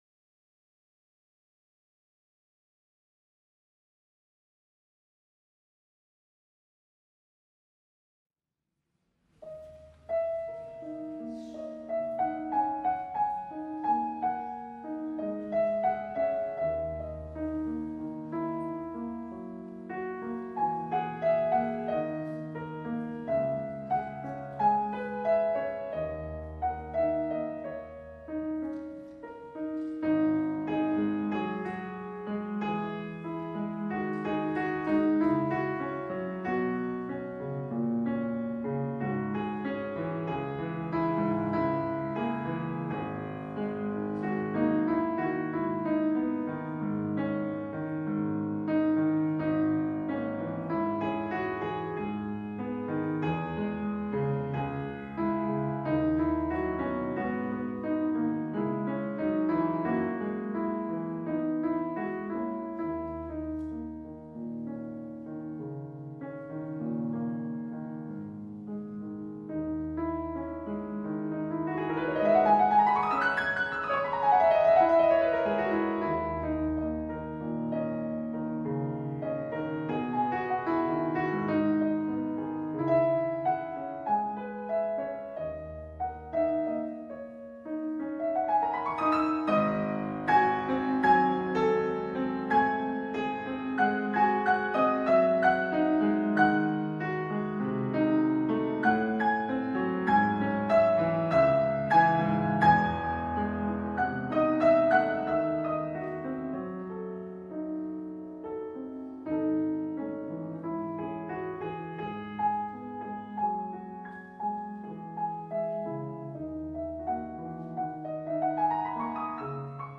Concert pianist, chamber music artist, piano accompanist, piano teacher.
PIANIST – OFFICIAL WEB SITE